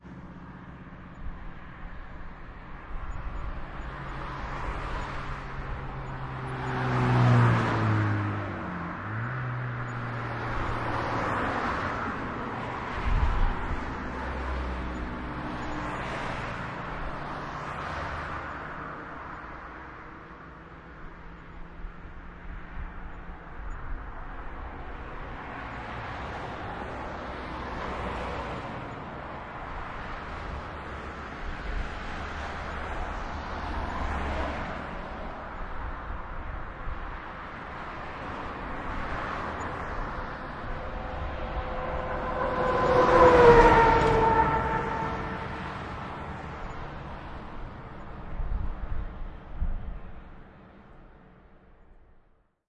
Tag: 道路 现场录音 交通 街道 汽车